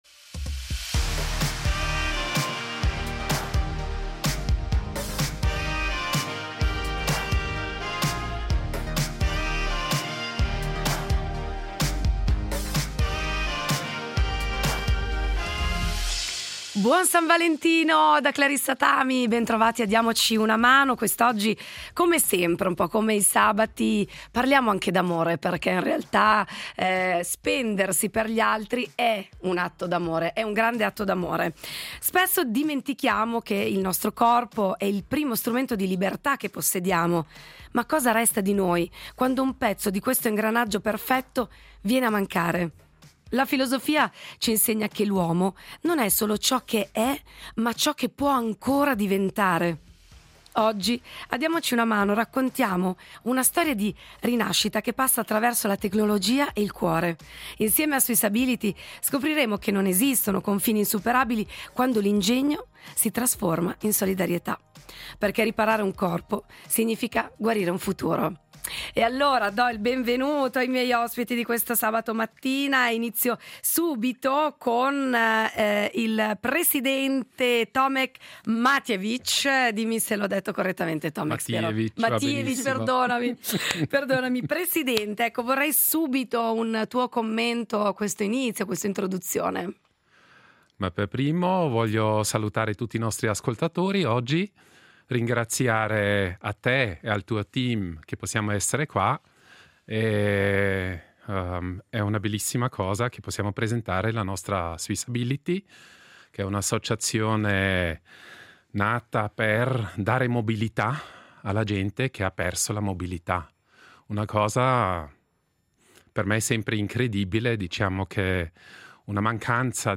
In studio le voci di SwissAbility .